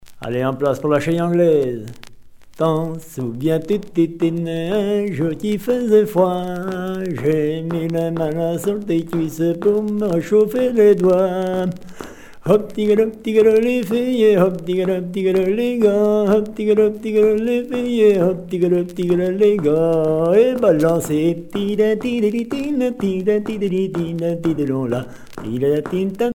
danse : quadrille : chaîne anglaise
Pièce musicale éditée